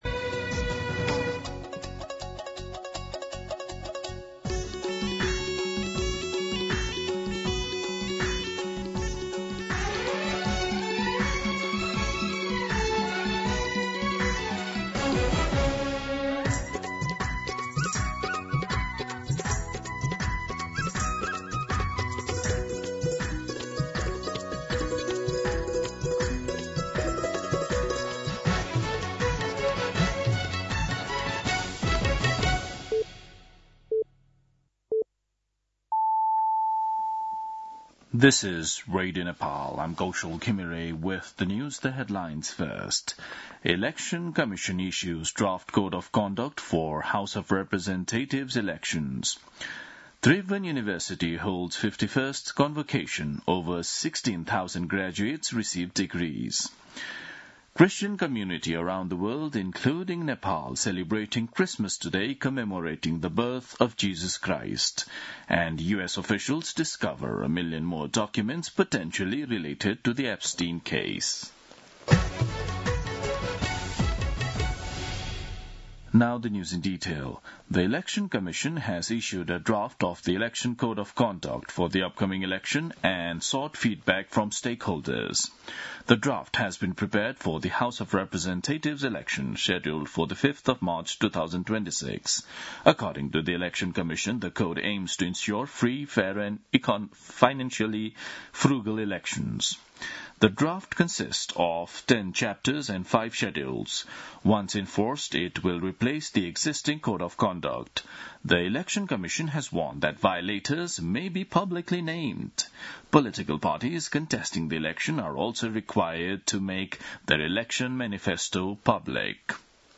दिउँसो २ बजेको अङ्ग्रेजी समाचार : १० पुष , २०८२
2-pm-English-News-4.mp3